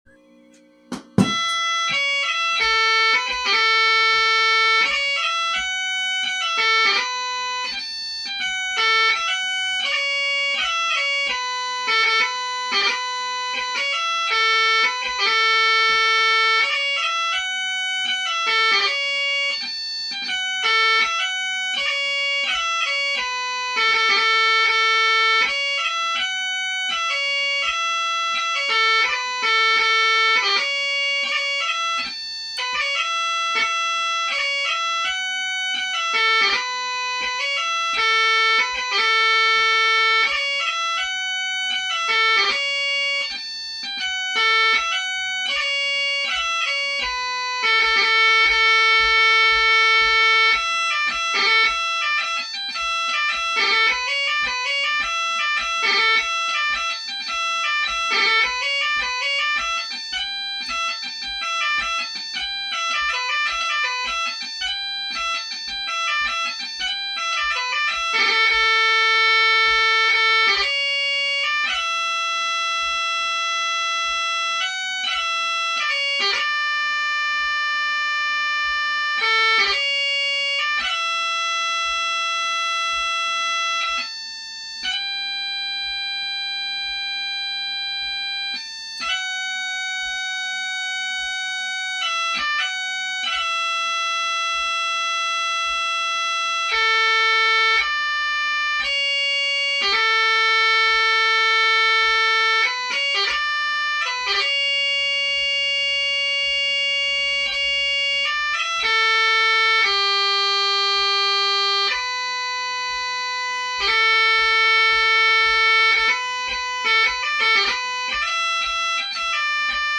Full Set Audio (Bagpipes)